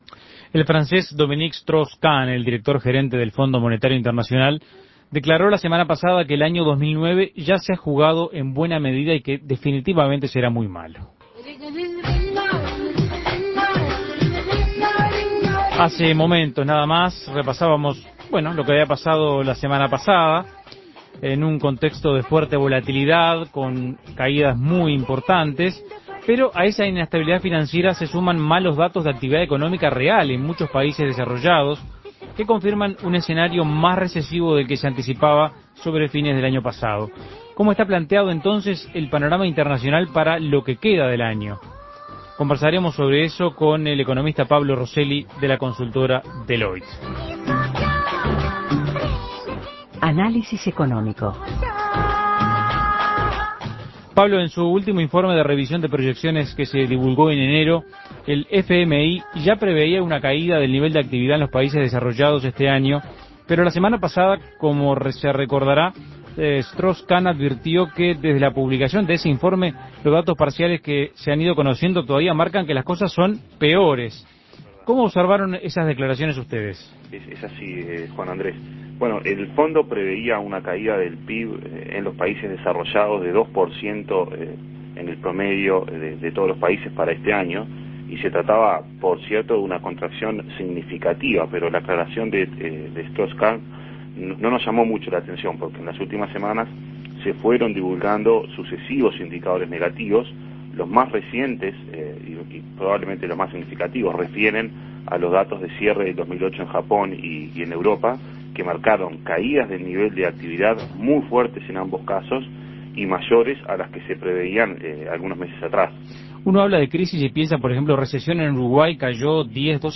Análisis Económico ¿Qué indican las últimas cifras de crecimiento económico a nivel mundial y cuáles son las perspectivas?